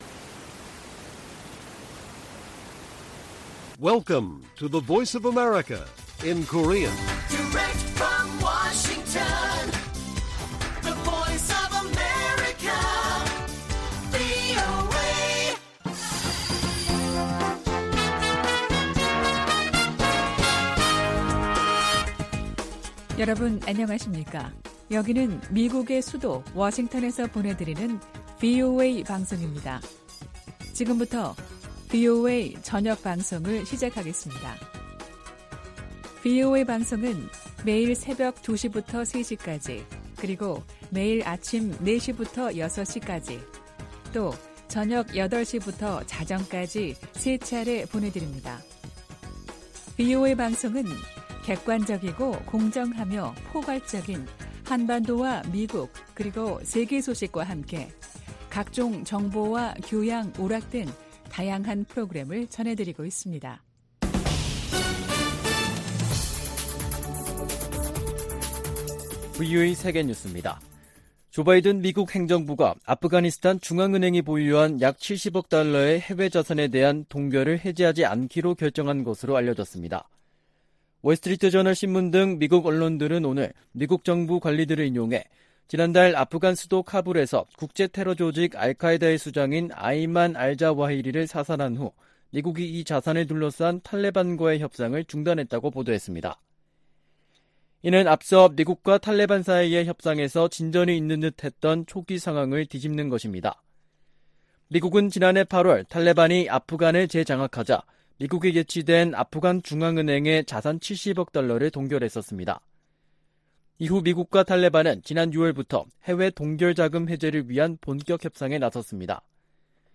VOA 한국어 간판 뉴스 프로그램 '뉴스 투데이', 2022년 8월 16일 1부 방송입니다. 미국과 한국, 일본, 호주, 캐나다 해군이 하와이 해역에서 ‘퍼시픽 드래곤’ 훈련을 진행했습니다. 미 국무부는 한국 윤석열 대통령이 언급한 ‘담대한 구상’과 관련해 북한과 외교의 길을 모색하는 한국 정부를 강력히 지지한다고 밝혔습니다.